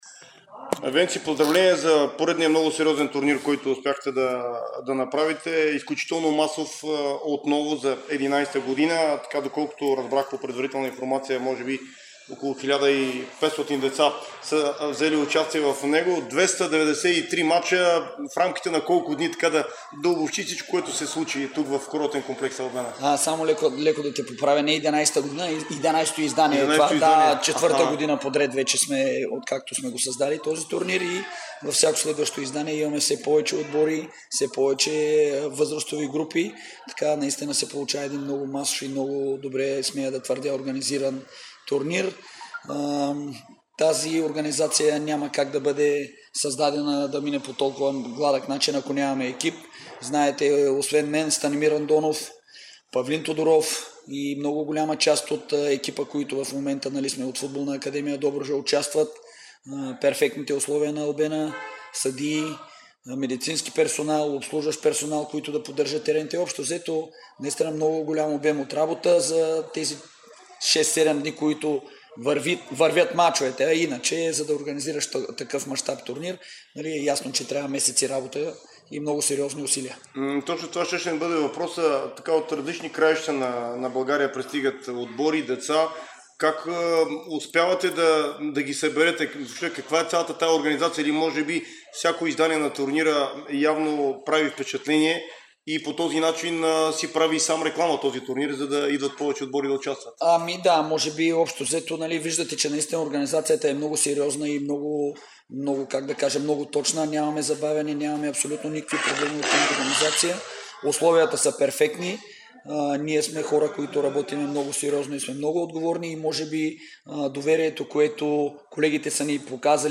Чуйте цялото интервю в приложения звуков файл!